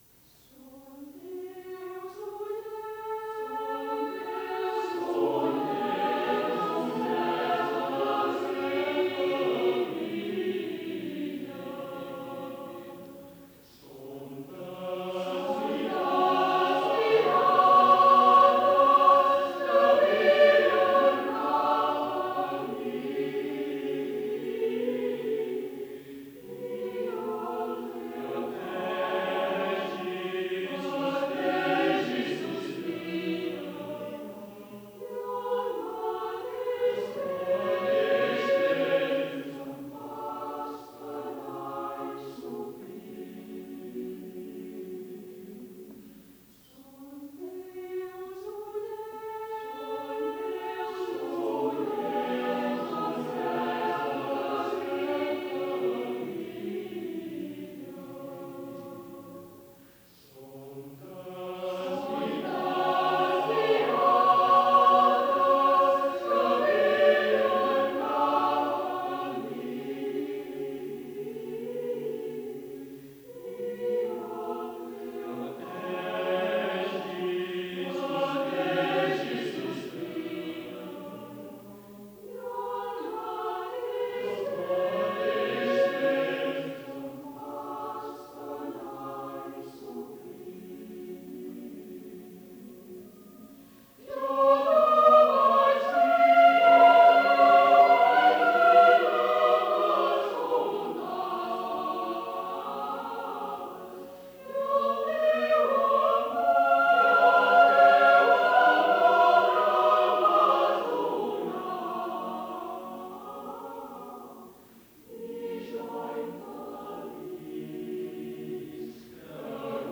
Cançó popular menorquina per a cor mixt a cappella
Cor mixt a cappella